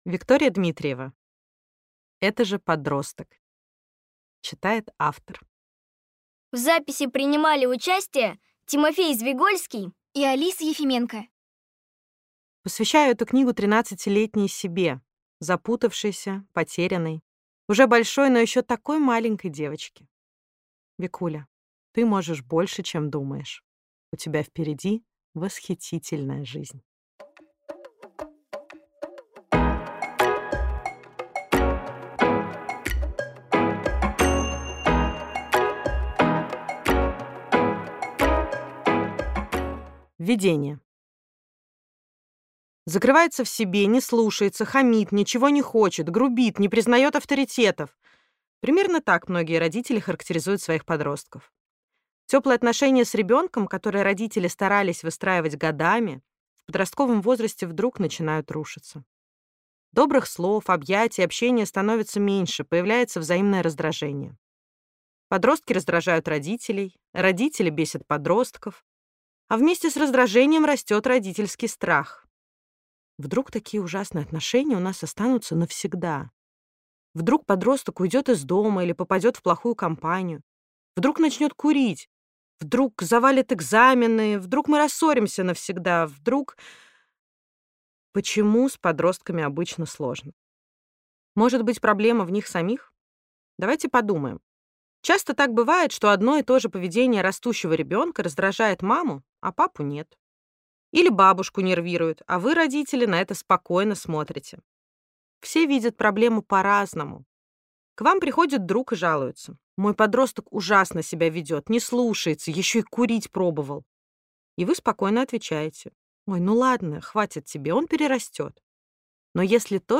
Аудиокнига Это же подросток! Как жить и общаться с детьми, когда они взрослеют | Библиотека аудиокниг